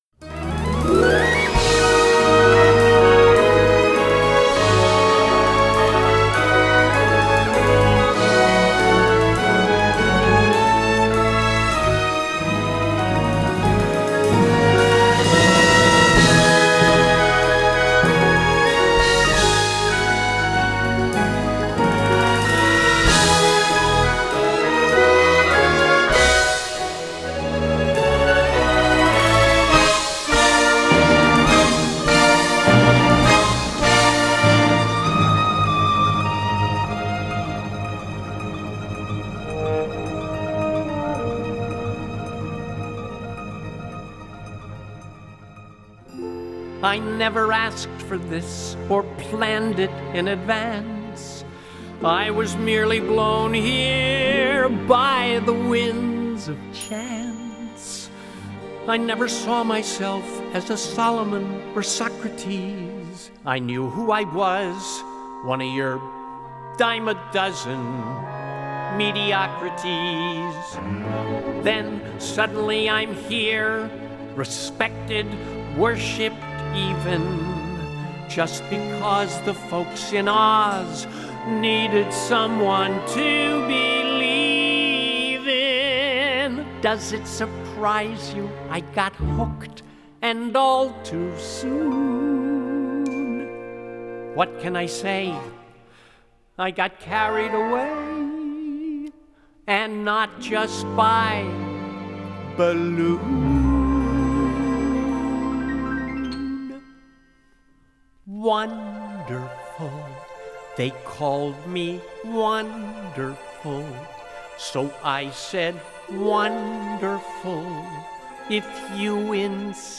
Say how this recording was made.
Original Broadway Cast Recording 2003